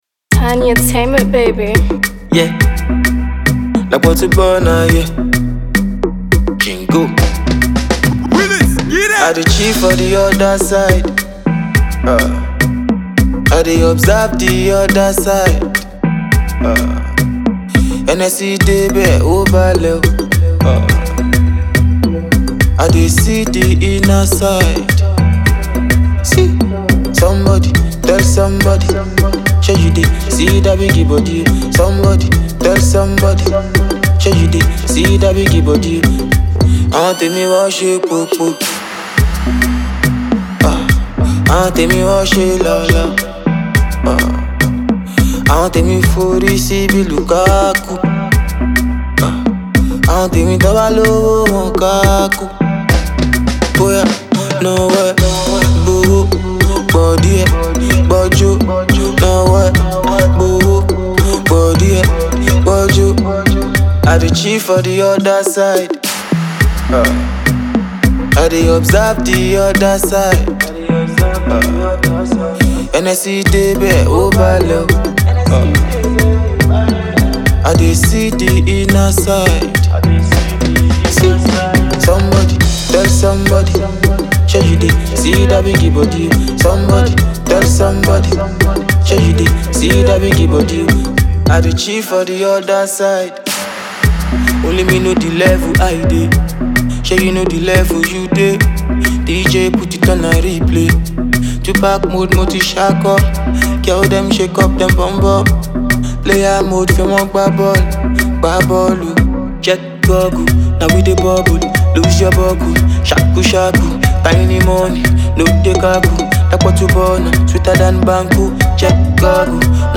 A true Afrobeat artiste